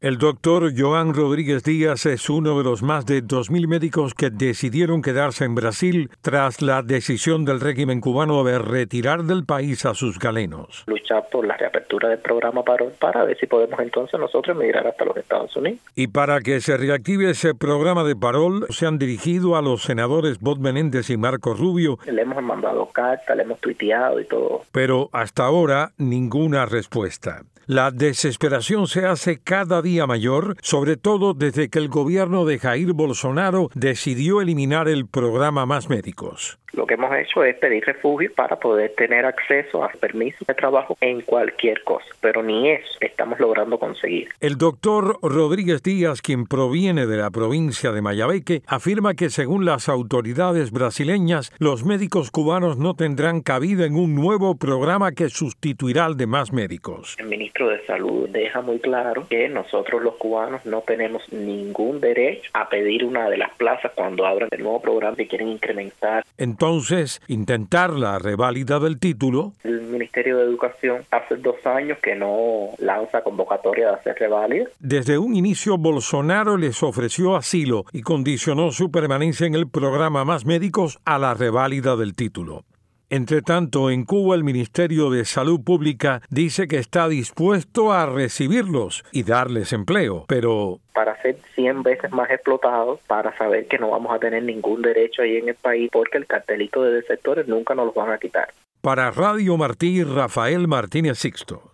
conversó con uno de estos médicos cubanos